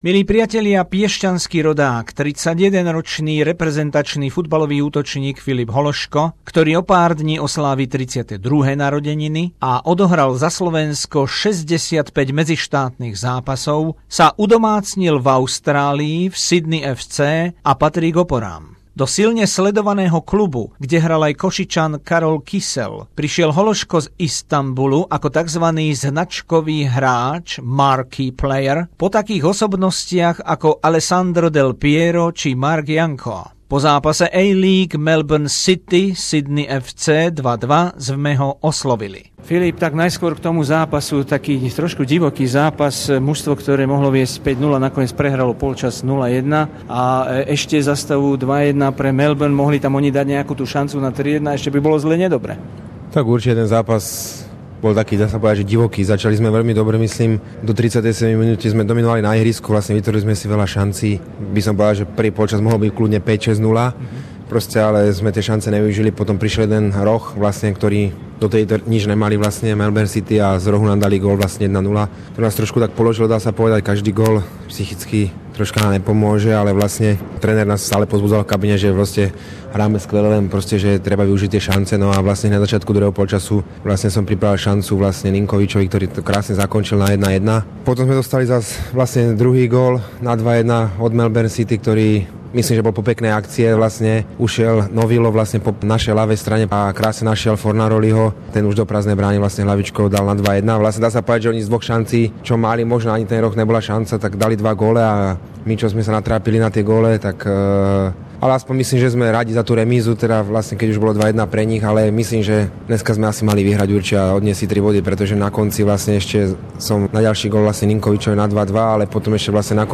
Rozhovor so slovenským futbalovým reprezentantom Filipom Hološkom, v súčasnosti hráčom Sydney FC